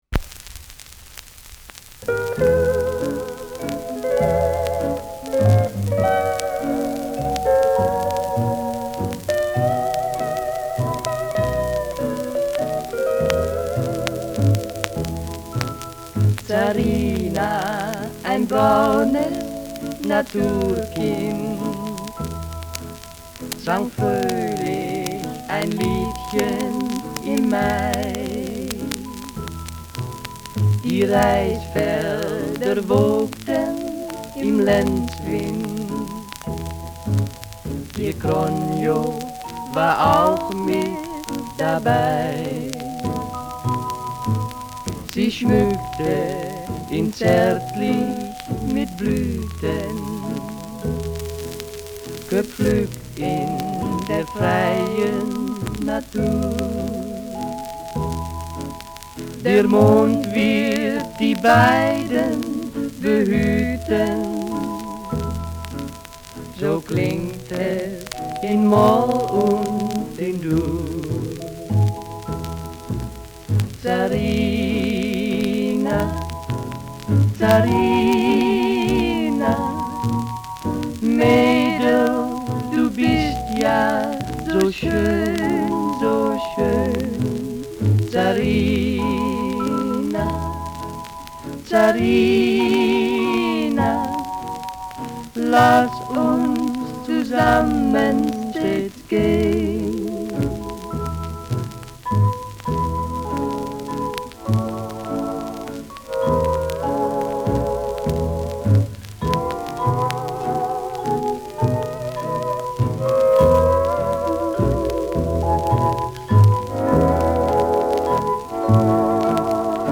Langsamer Walzer
Schellackplatte